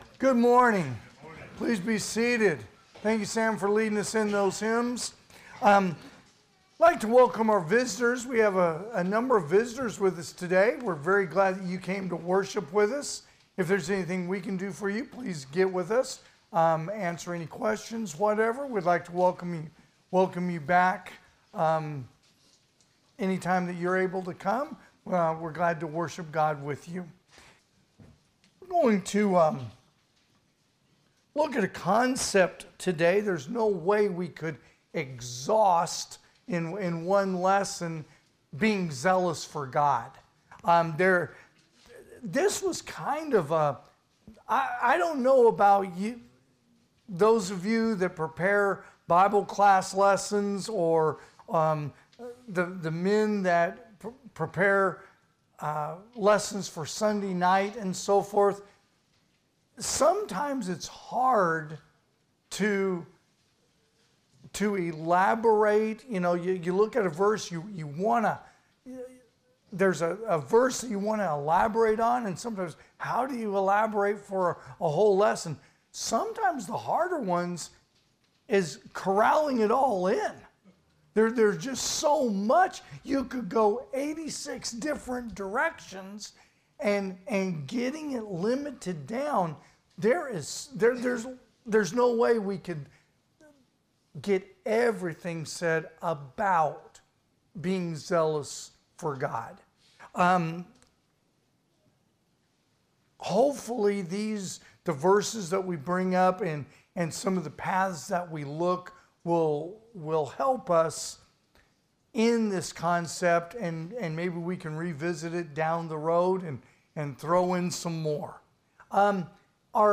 2026 (AM Worship) "Zealous For God"